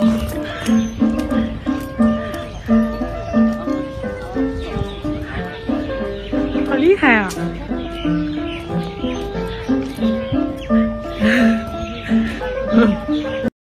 一阵悠扬悦耳的琴声
在江苏南京玄武湖公园内
声情并茂地演奏着
当蒸屉遇上欢快的琴声